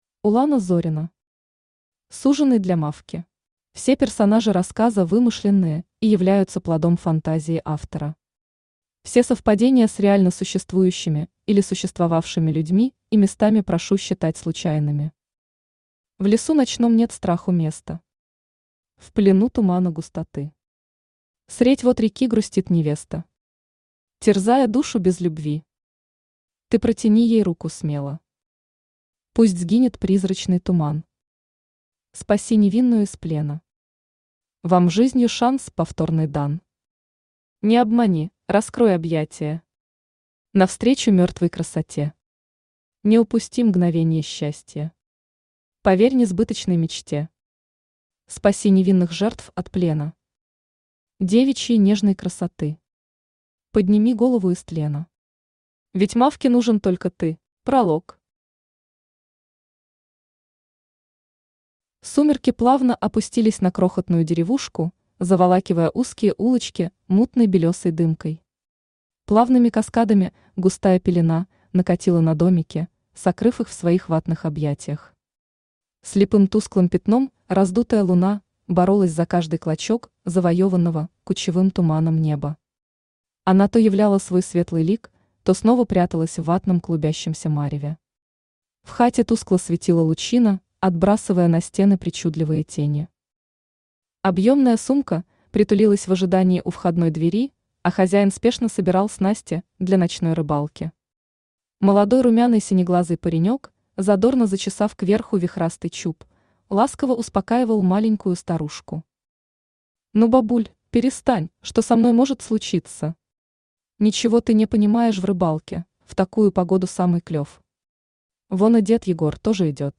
Аудиокнига Суженый для Мавки | Библиотека аудиокниг
Aудиокнига Суженый для Мавки Автор Улана Зорина Читает аудиокнигу Авточтец ЛитРес.